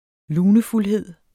Udtale [ ˈluːnəfulˌheðˀ ]